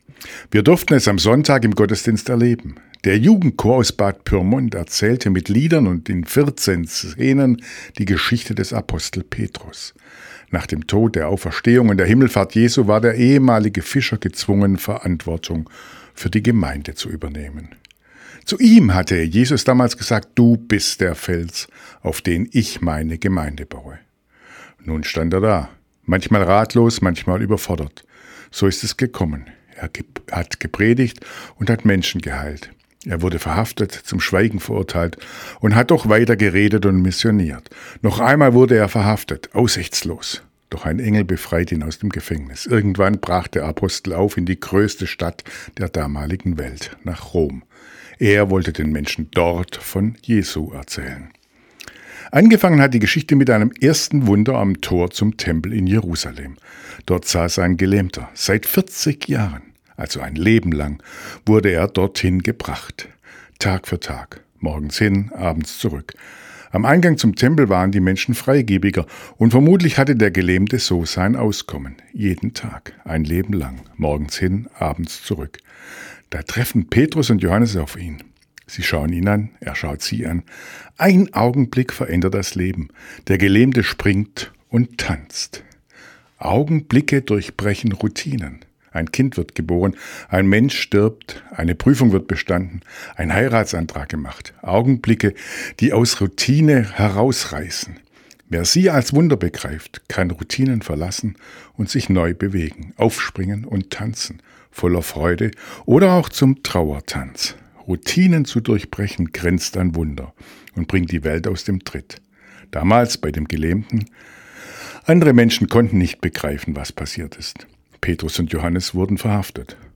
Radioandacht vom 9. September